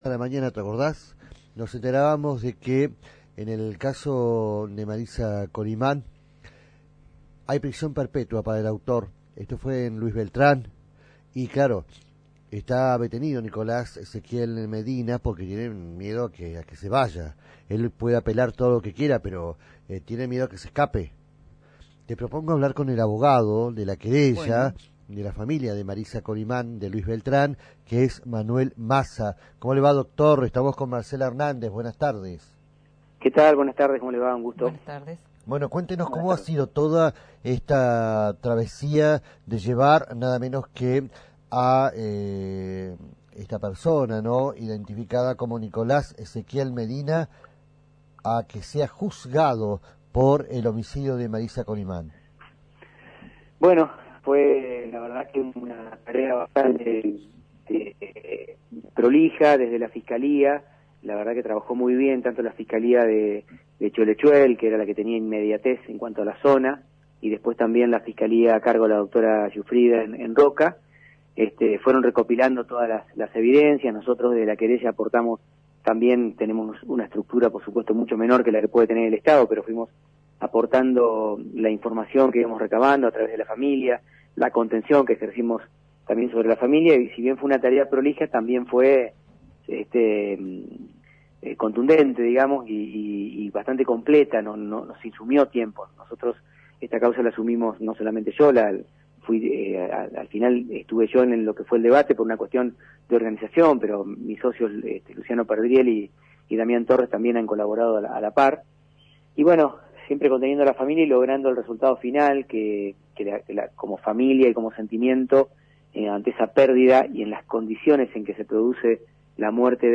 Escuchá la entrevista completa al abogado querellante